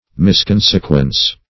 Misconsequence \Mis*con"se*quence\, n. A wrong consequence; a false deduction.